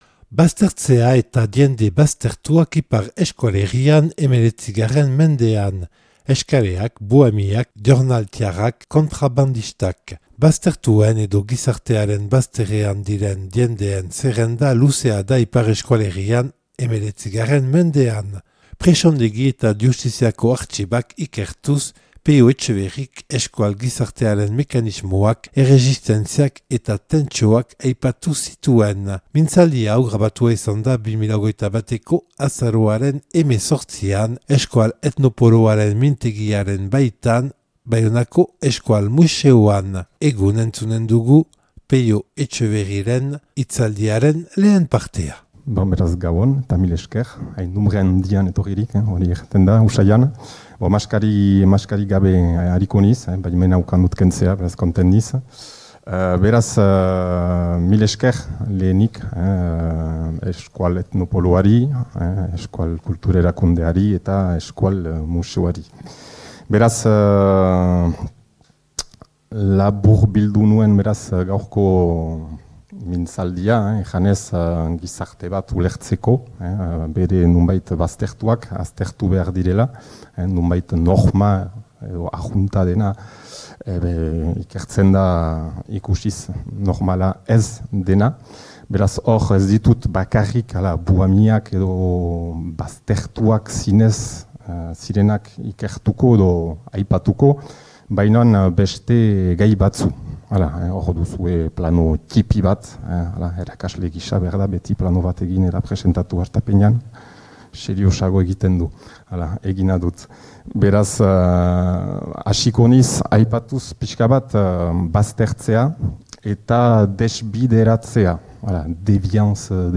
Euskal etnopoloaren mintegiaren baitan